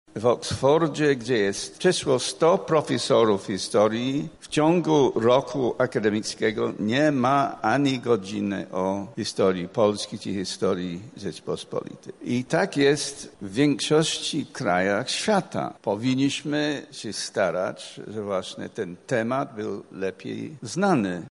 Profesor Uniwersytetu Londyńskiego Norman Davies podczas swojego wystąpienia poruszył temat światowego poziomu wiedzy dotyczącego Polski i jej historii: